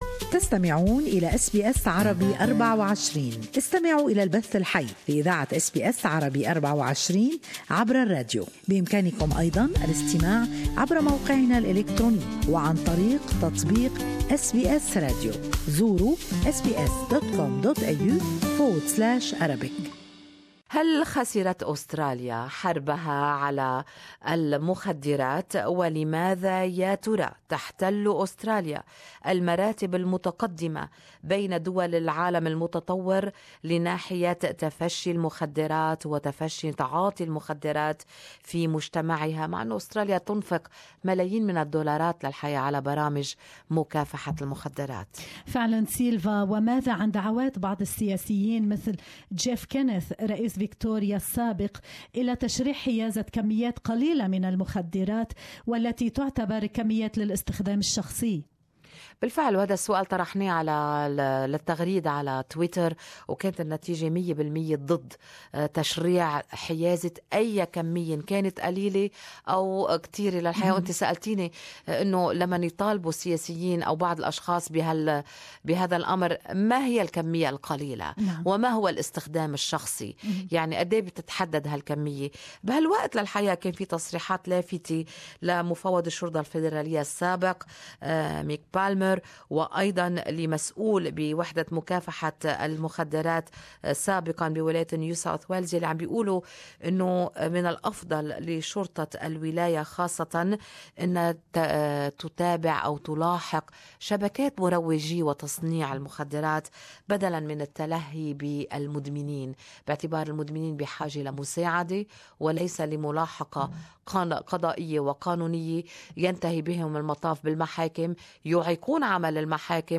Good Morning Australia listeners share their opinions on this topic.